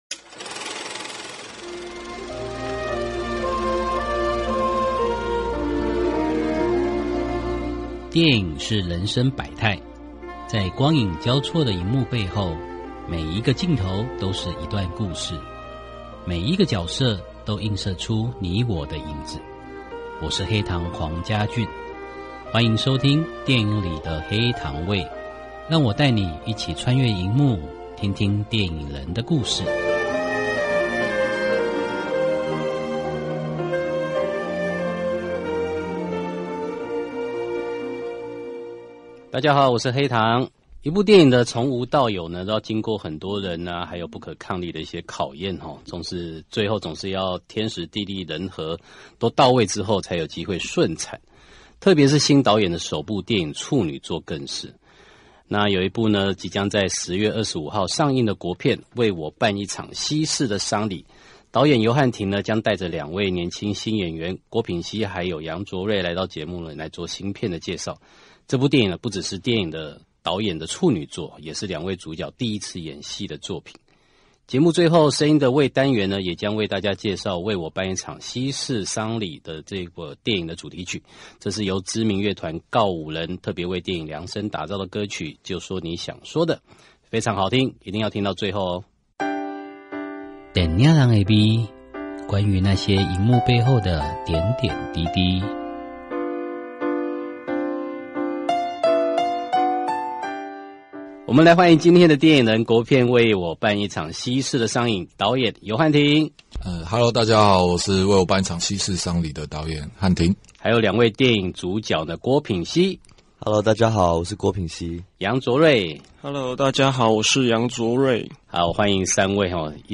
訪問大綱： 1.導演面對自己的第一部電影長片，做了哪些挑戰與嘗試？